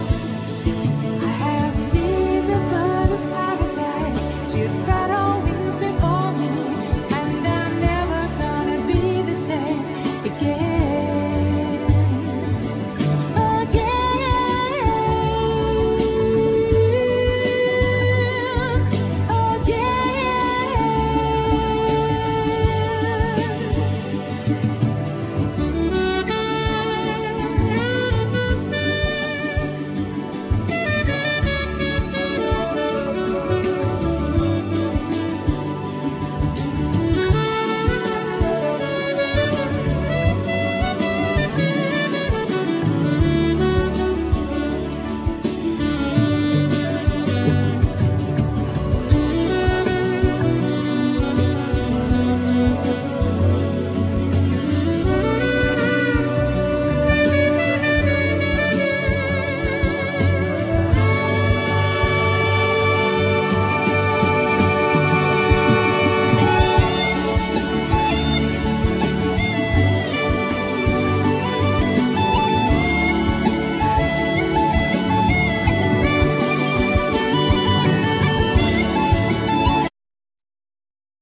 Vocal,Alto saxophone
Ac.guitar,Mandolin
Bouzuki,Bodhran,Coros
Tenor&Soprano saxophone,Synthesizer
Contra-bass,El.bass
Piano,Synthesizer
Drums,Percussions
Trumpet
Irish harp
Irish gaita,Flute